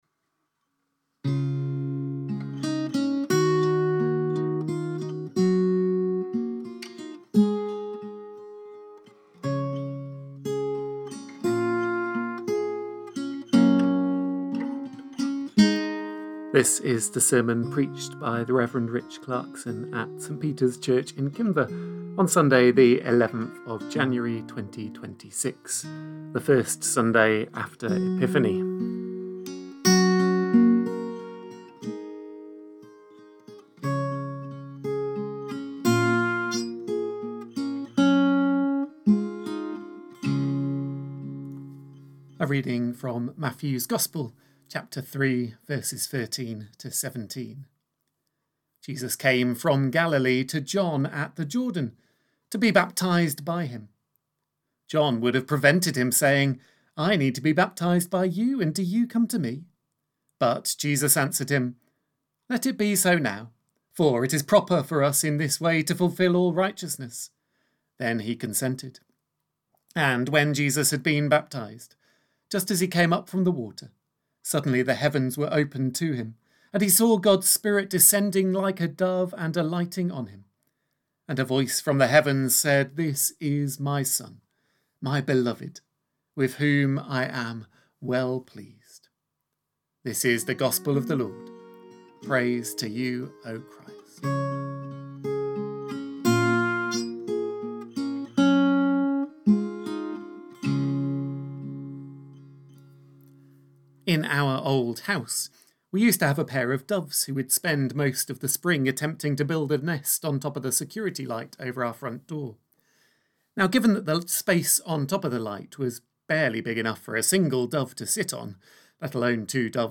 Sermon recordings - St Peter's Kinver